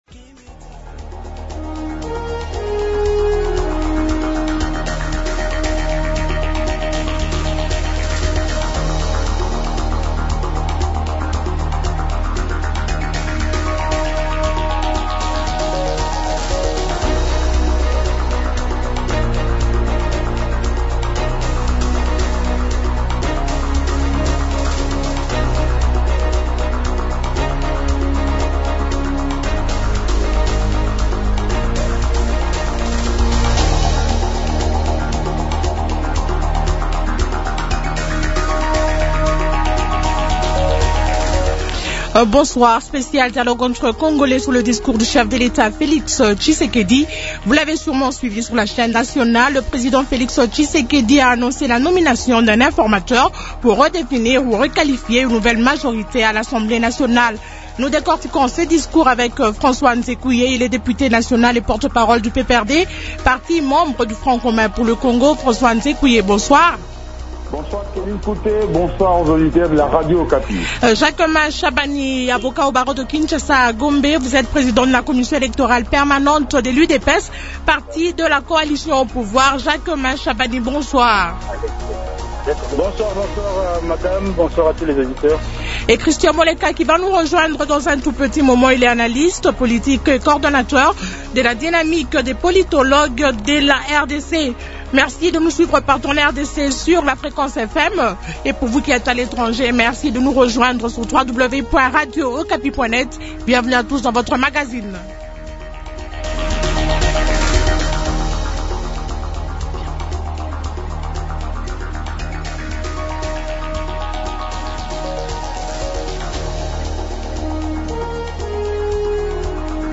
» Intervenants François Nzekuye, député national et porte-parole du PPRD, parti membre du Front commun pour le Congo (FCC).